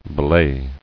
[be·lay]